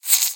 Minecraft Version Minecraft Version 1.21.4 Latest Release | Latest Snapshot 1.21.4 / assets / minecraft / sounds / mob / silverfish / say4.ogg Compare With Compare With Latest Release | Latest Snapshot